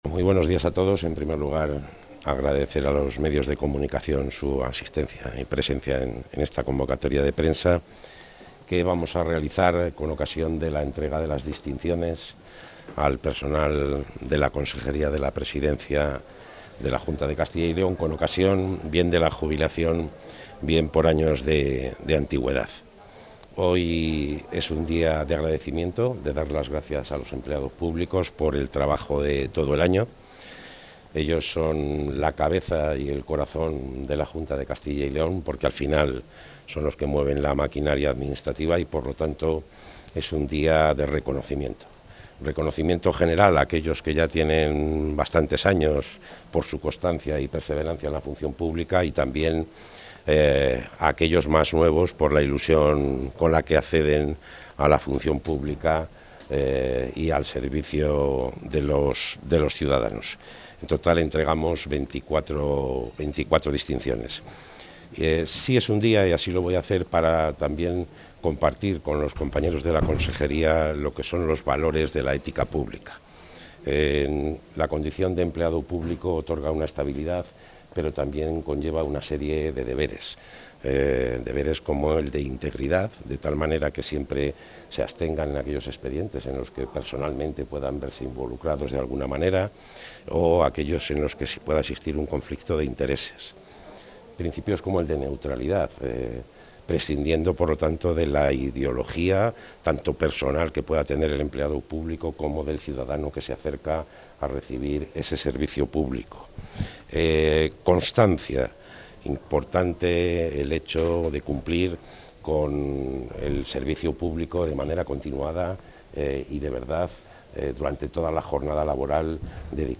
Declaraciones del consejero.
Así lo ha anunciado el consejero de la Presidencia, Luis Miguel González Gago, durante la entrega de las distinciones a los empleados públicos de la Consejería por razón de jubilación o cumplimiento de años de servicio. De esta manera, culmina un largo y laborioso trayecto que ha incluido la convocatoria de 184 procesos selectivos y la tramitación de más de 38.000 solicitudes.